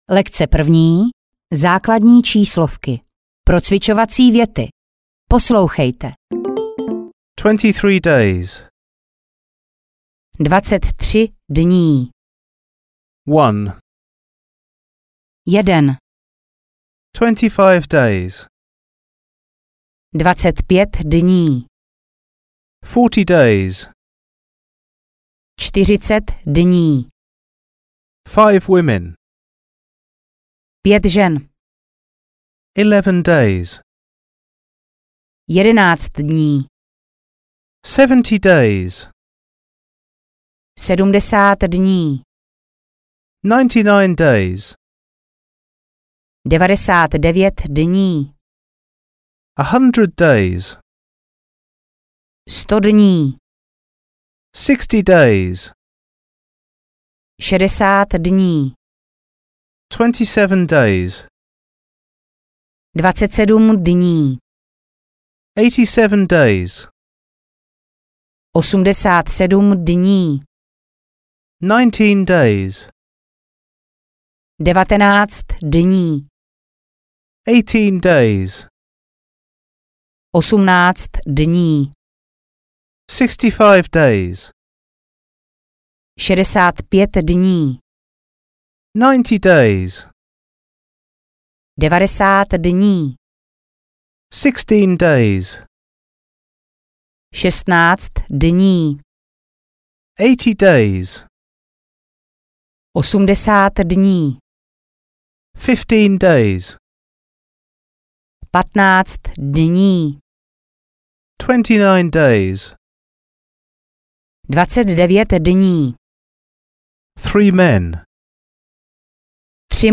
Anglická gramatika A1, A2 audiokniha
Ukázka z knihy